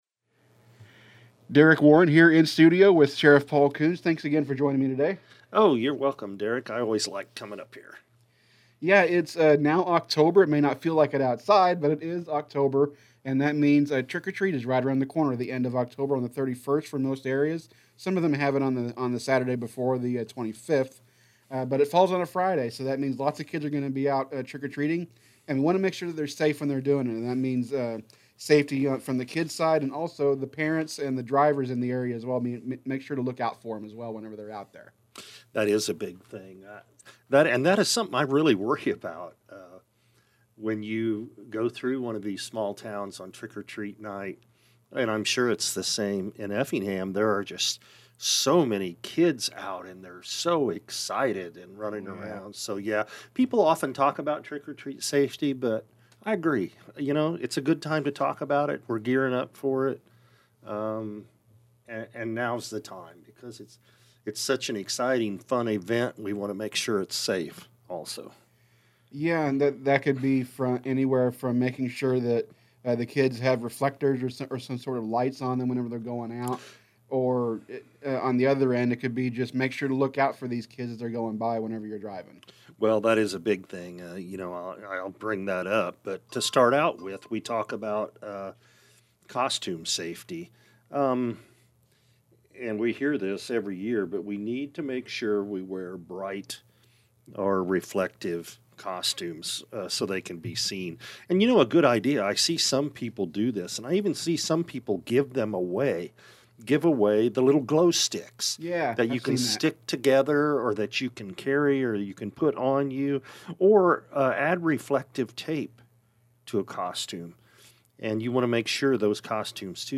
Interview With Sheriff Kuhns Reminds To Keep Safety First This Halloween